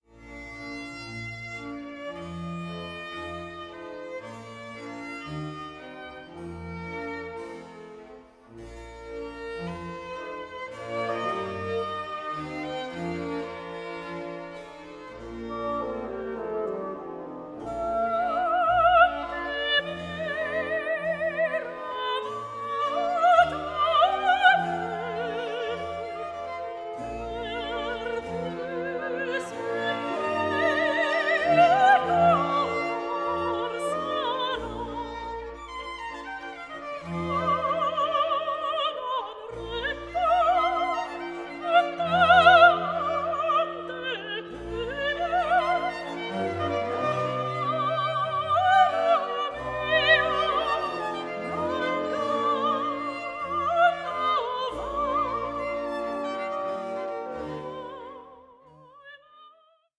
PASSIONATE VOCAL AND SYMPHONIC MUSIC FROM THE CLASSICAL ERA
period-instruments ensembles